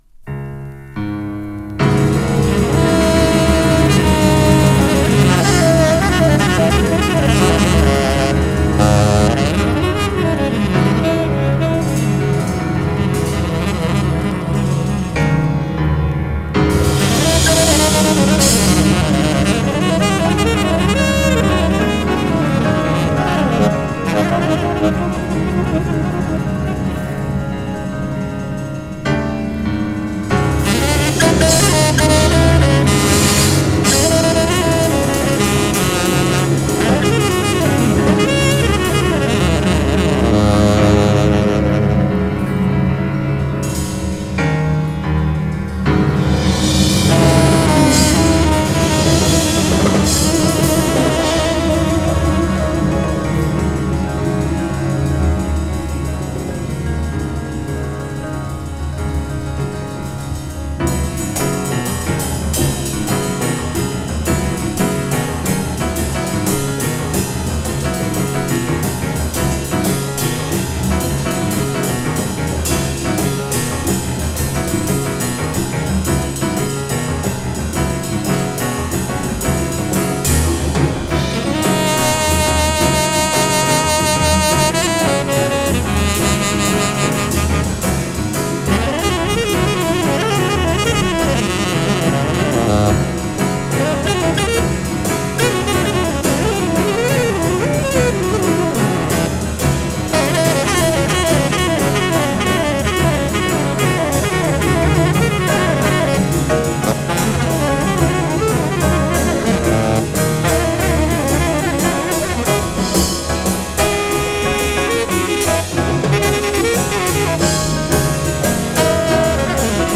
Format 2LP